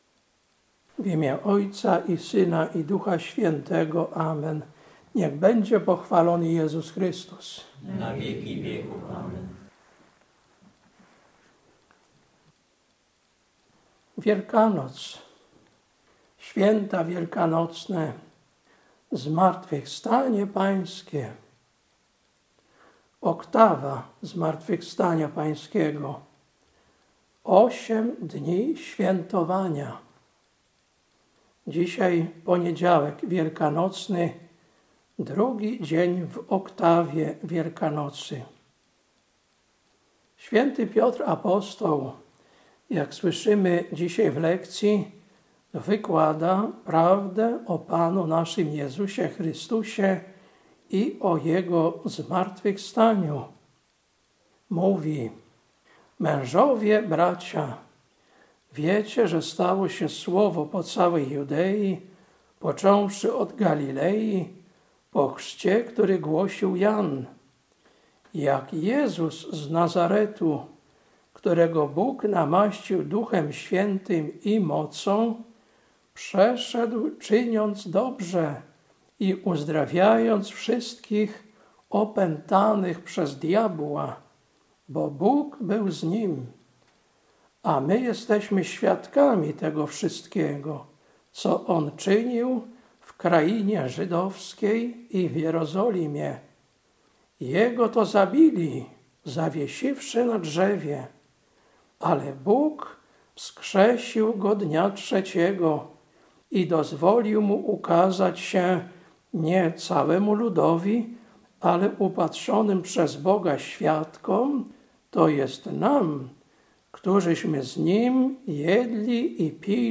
Kazanie na PONIEDZIAŁEK W OKTAWIE WIELKANOCY, 6.04.2026 Lekcja: Dz 10, 37-43 Ewangelia: Łk 24, 13-35